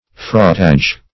Fraughtage \Fraught"age\